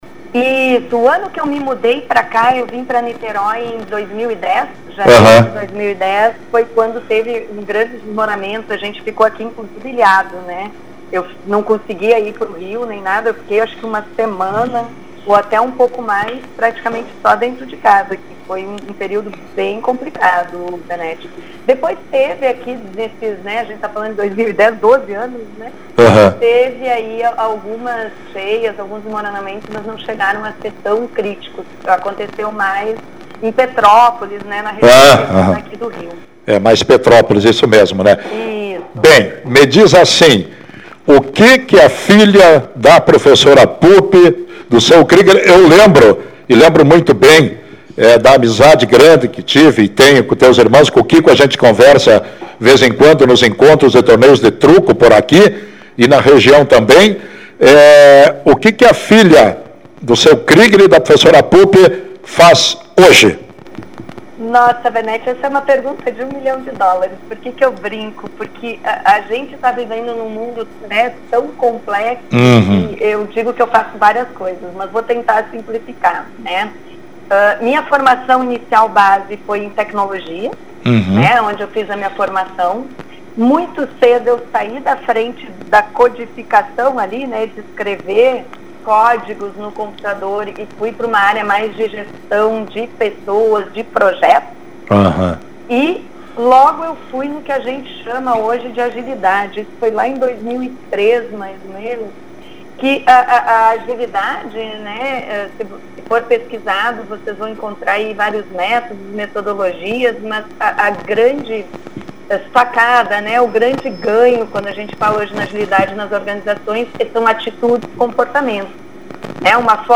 Essa semana tive uma oportunidade muito bacana de dar um entrevista na rádio da minha cidade Natal, rádio Moriá FM.